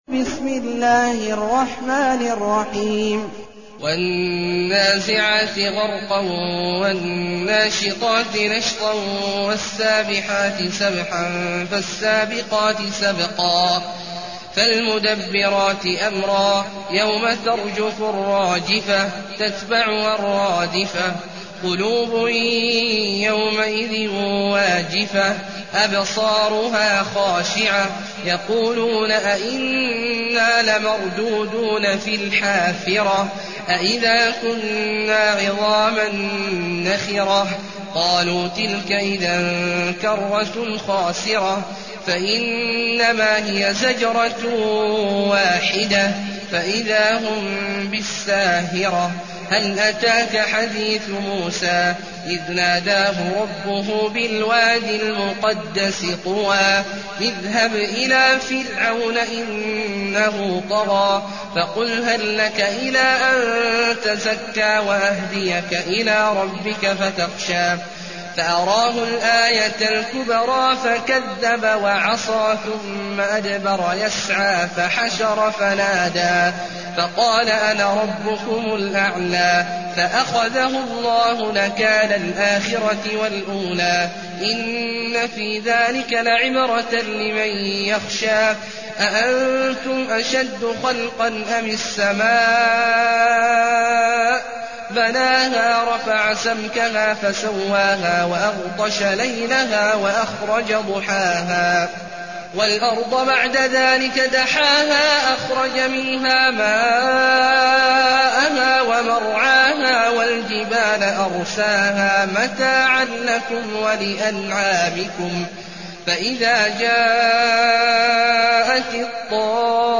المكان: المسجد الحرام الشيخ: عبد الله عواد الجهني عبد الله عواد الجهني النازعات